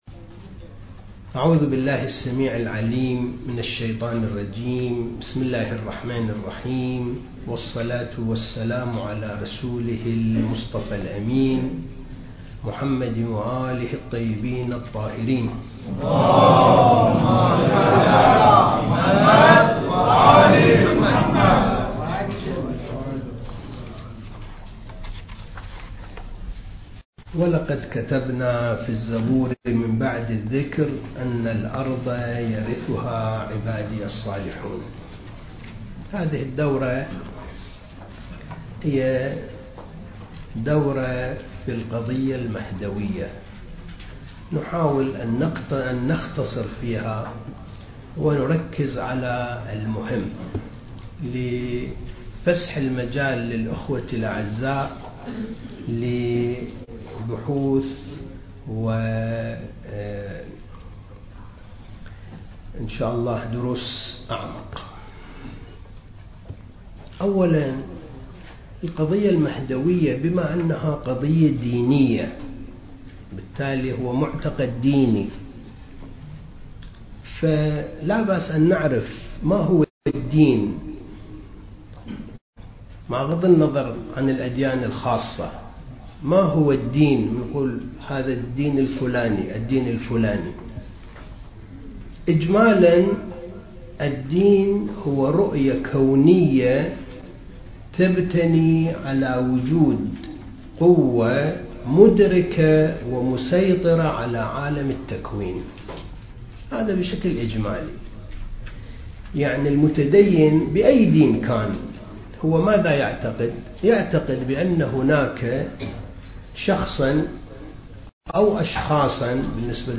دورة الثقافة المهدوية (1) المكان: معهد وارث الأنبياء (عليهم السلام) لإعداد المبلغين العتبة الحسينية المقدسة